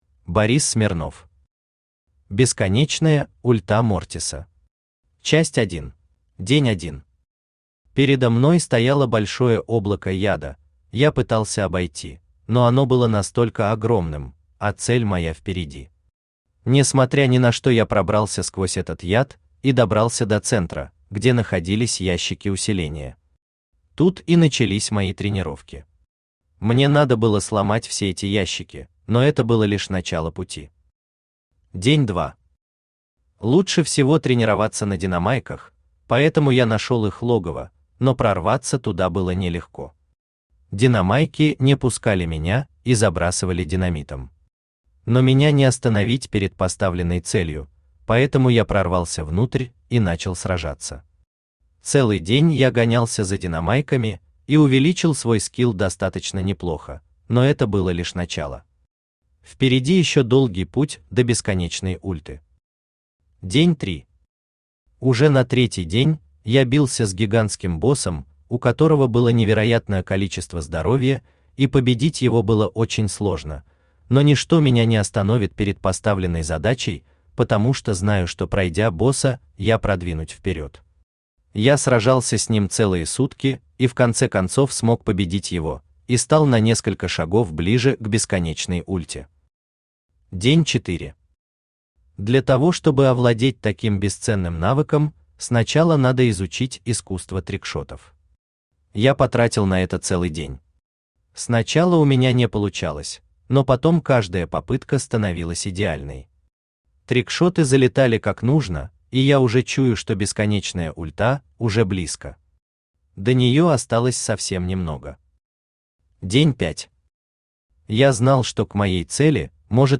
Аудиокнига Бесконечная ульта Мортиса. Часть 1 | Библиотека аудиокниг
Часть 1 Автор Борис Ильич Смирнов Читает аудиокнигу Авточтец ЛитРес.